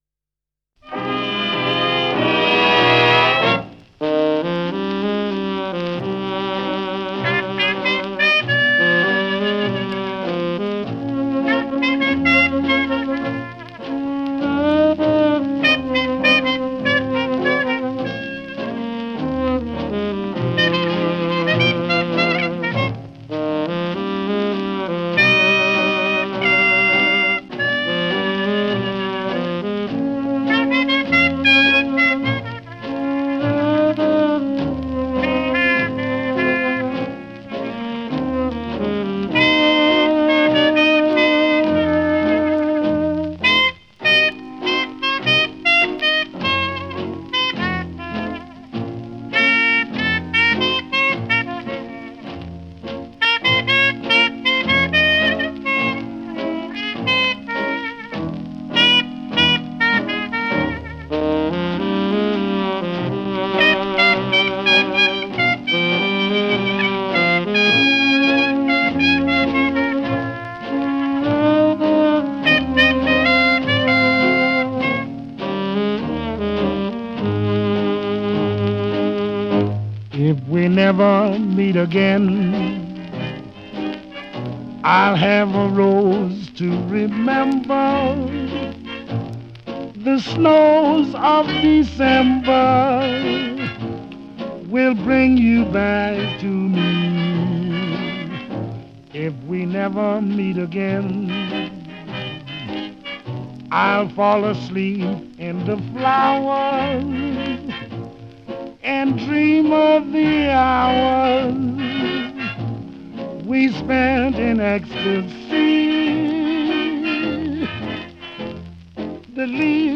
Big Band
• BALLAD (JAZZ)
• VOCAL (JAZZ)
• Vocal
• Trumpet
• Tenor Sax
• Piano
• Guitar
• Bass
• Drums